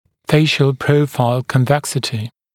[‘feɪʃl ‘prəufaɪl kɔn’veksətɪ]][‘фэйшл ‘проуфайл кон’вэксэти]выпуклость профиля лица